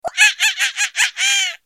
دانلود آهنگ میمون 10 از افکت صوتی انسان و موجودات زنده
جلوه های صوتی
دانلود صدای میمون 10 از ساعد نیوز با لینک مستقیم و کیفیت بالا